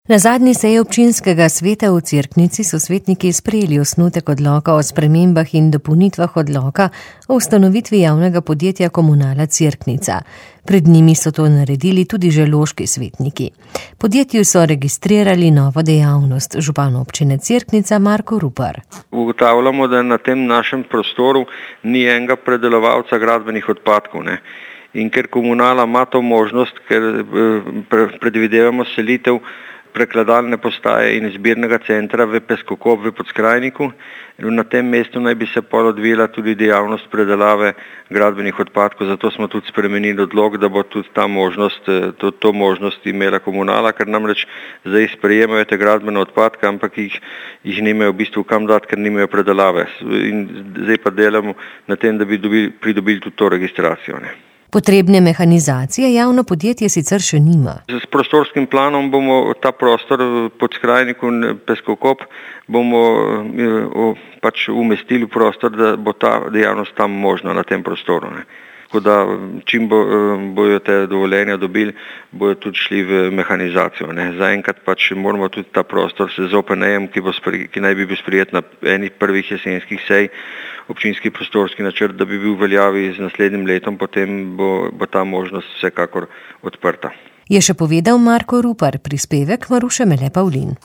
Registrirali so novo dejavnost. Več v pogovoru z županom Občine Cerknica Markom Ruparjem.